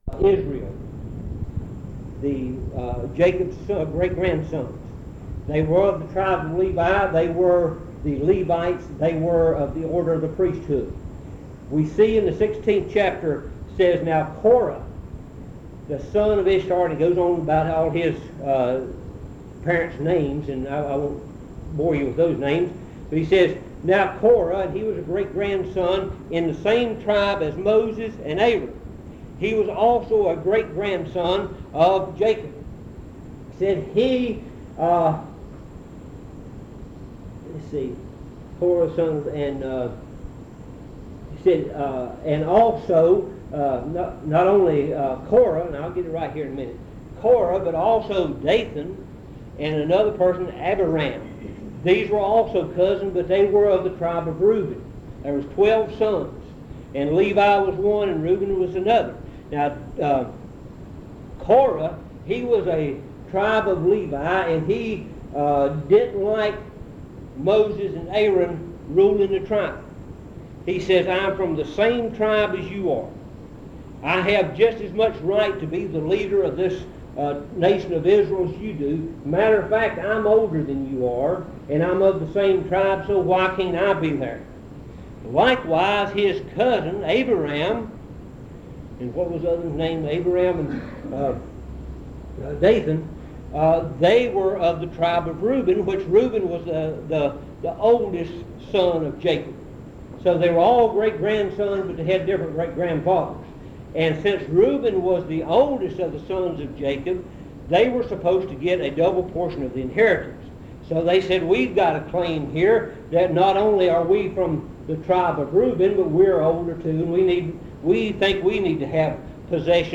In Collection: Monticello Primitive Baptist Church audio recordings Thumbnail Titolo Data caricata Visibilità Azioni PBHLA-ACC.002_014-A-01.wav 2026-02-12 Scaricare PBHLA-ACC.002_014-B-01.wav 2026-02-12 Scaricare